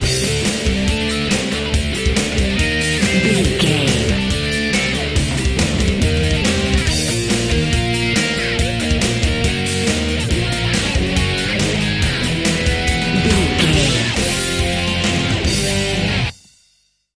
Aeolian/Minor
drums
electric guitar
bass guitar
pop rock
hard rock
lead guitar
aggressive
energetic
intense
nu metal
alternative metal